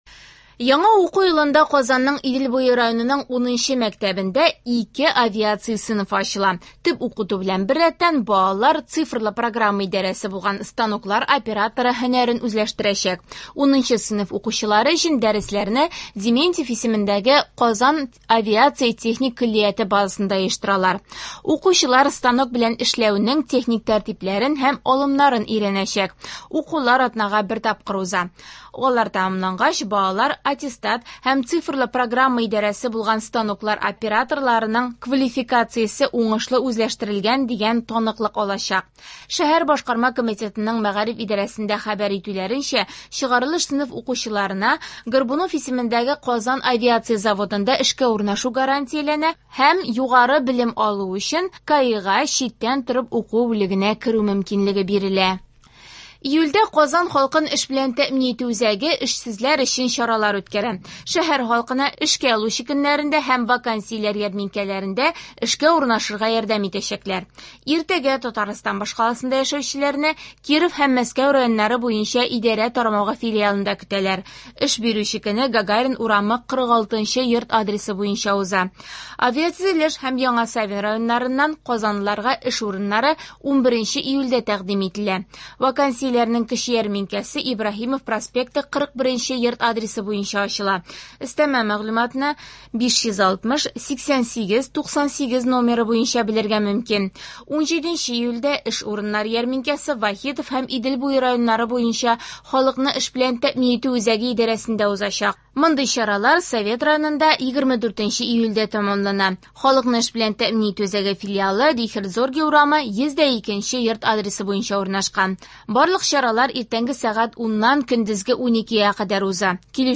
Яңалыклар (08.07.24)